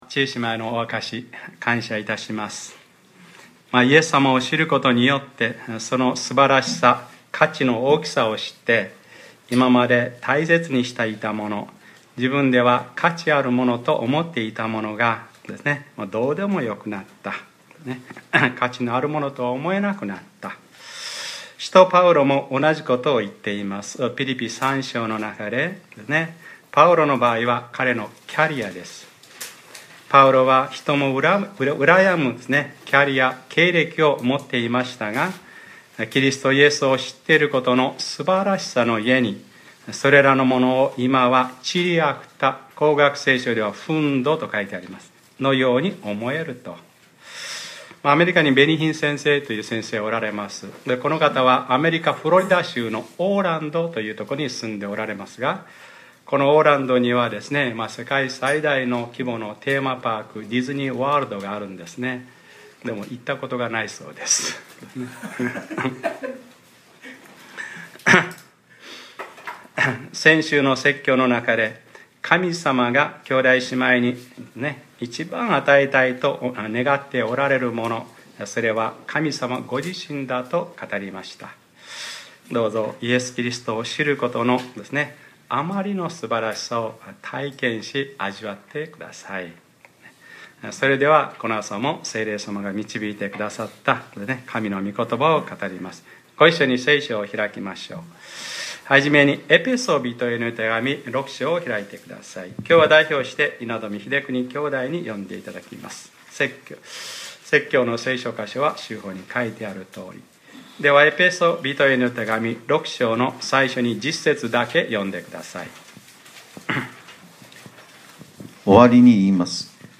2014年7月13日（日）礼拝説教 『私たちの格闘は血肉に対するものではなく』 | クライストチャーチ久留米教会